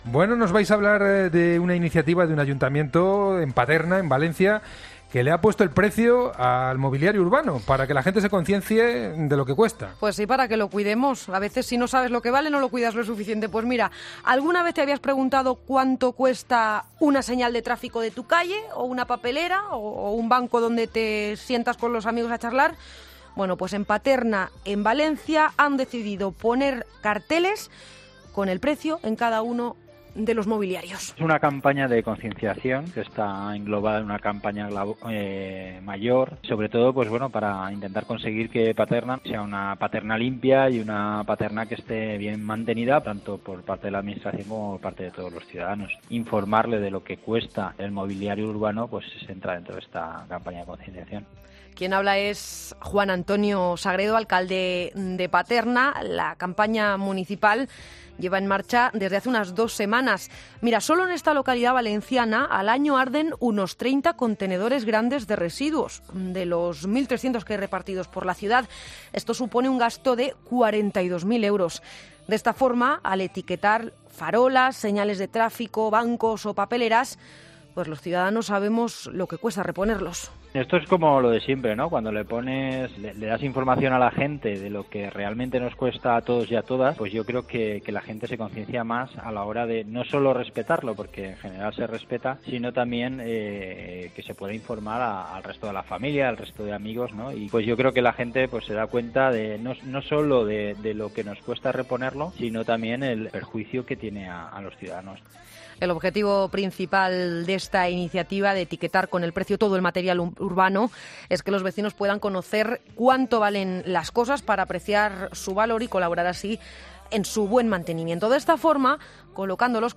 Entrevistas en Mediodía COPE
El alcalde del Ayuntamiento de Paterna, Juan Antonio Sagredo, nos explica en "Mediodía Cope" el sentido del proyecto